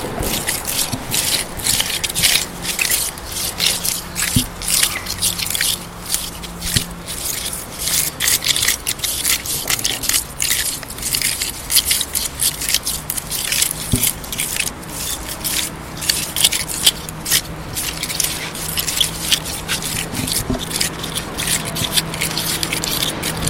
6 anni Indizi dei bambini È un rumore calmo e grattoso, per farlo venire bisogna fare il solletico a un “legno alto con i capelli”.
albero-slide-10.mp3